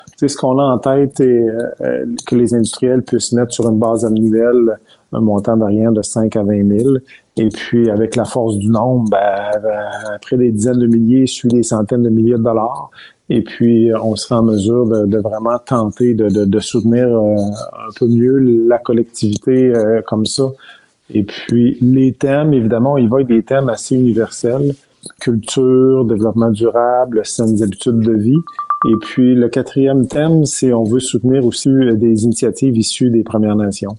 C’est ce qui a été annoncé en conférence de presse.